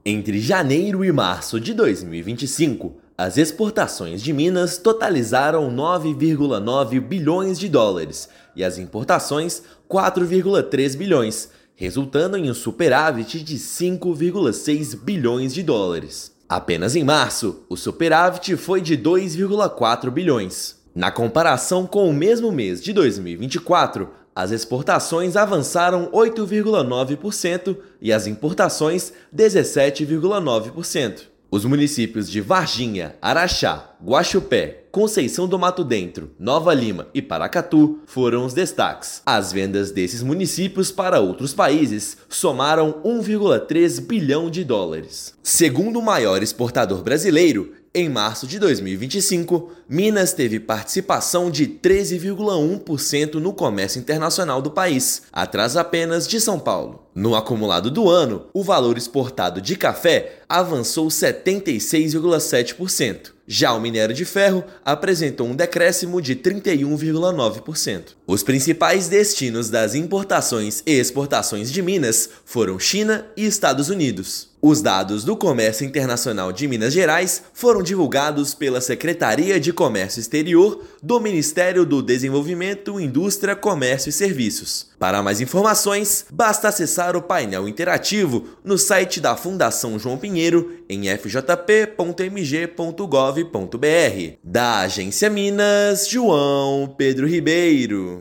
Painel interativo da FJP mostra que Varginha, Araxá, Guaxupé, Conceição do Mato Dentro, Nova Lima e Paracatu exportaram, juntos, US$ 1,3 bilhão no período. Ouça matéria de rádio.